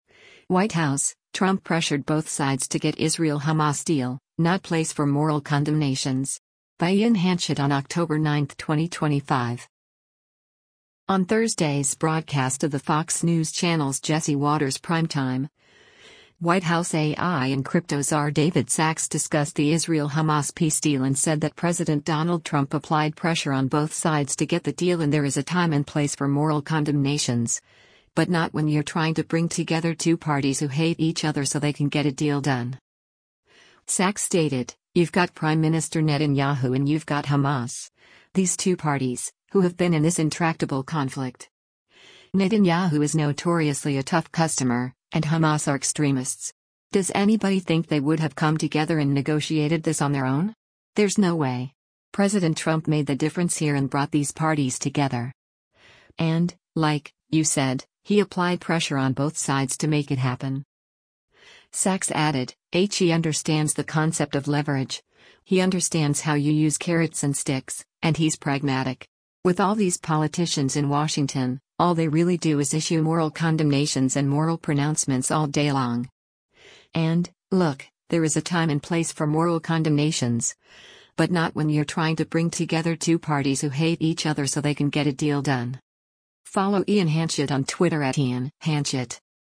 On Thursday’s broadcast of the Fox News Channel’s “Jesse Watters Primetime,” White House A.I. and Crypto Czar David Sacks discussed the Israel-Hamas peace deal and said that President Donald Trump “applied pressure on both sides” to get the deal and “there is a time and place for moral condemnations, but not when you’re trying to bring together two parties who hate each other so they can get a deal done.”